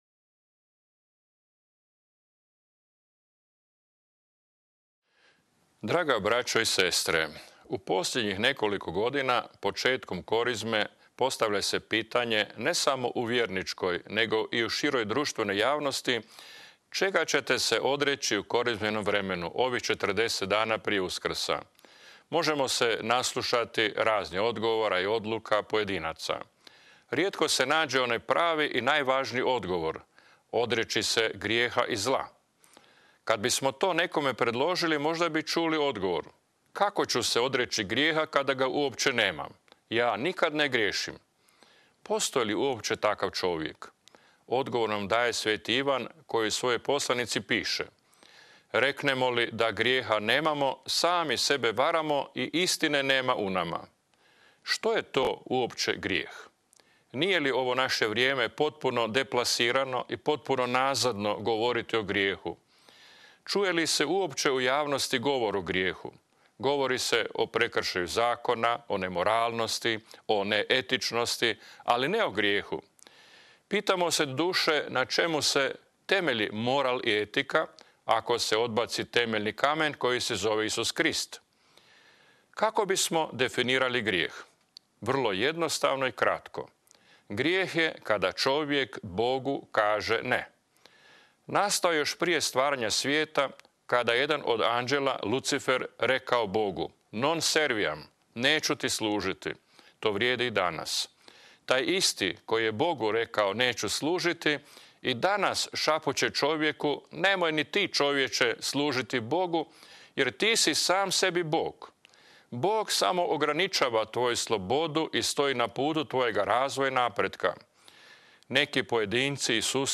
Snimanje poruke realizirao je Ured za pastoral u medijima u suradnji s Varaždinskom televizijom.
U nastavku objavljujemo cjelovitu uskrsnu poruku biskupa Josipa Mrzljaka: